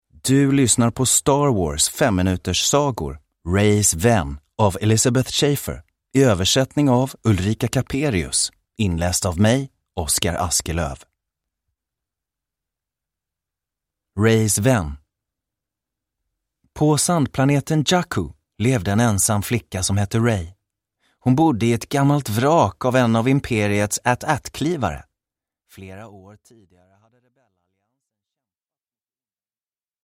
Reys vän. Den tionde berättelsen ur Star Wars 5-minuterssagor – Ljudbok – Laddas ner